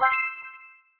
level up 2.mp3